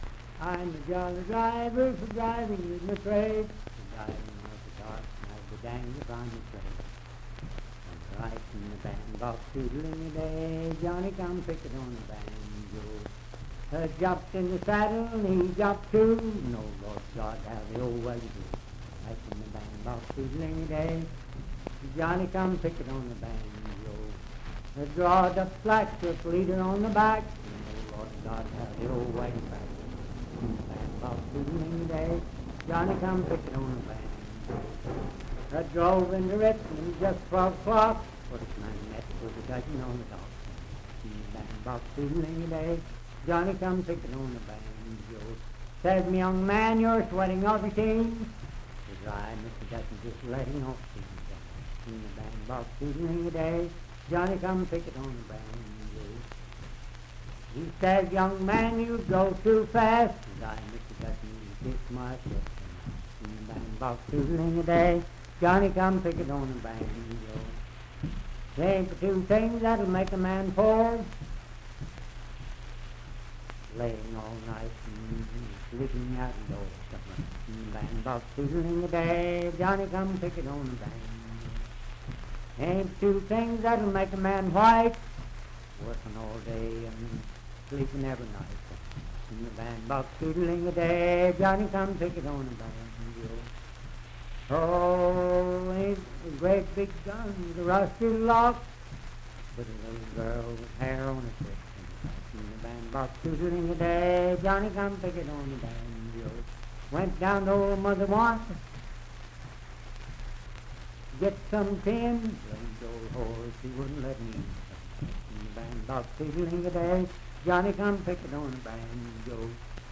Unaccompanied vocal music
Bawdy Songs
Voice (sung)